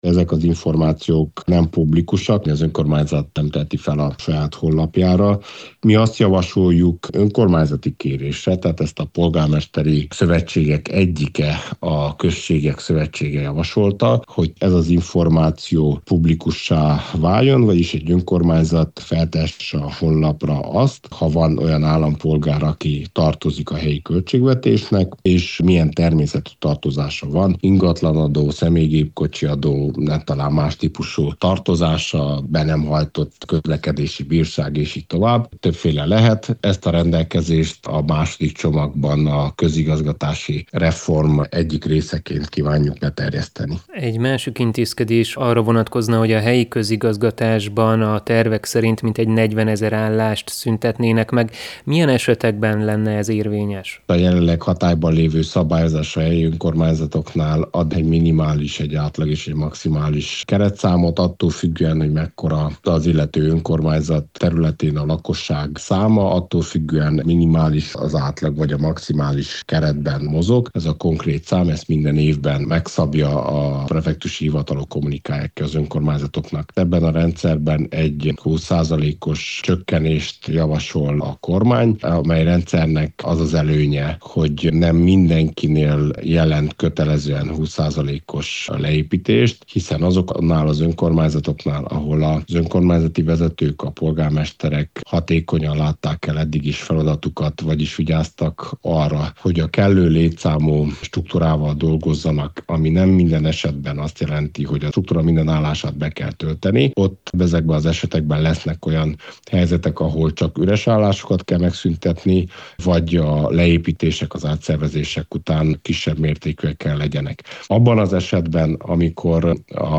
Cseke Attila fejlesztési miniszter szerint ez nem garantálná, hogy mindenki rendezi tartozásait, aki felkerül a szégyenlistára, azonban úgy véli, lennének, akiket ez ösztönözni tudna.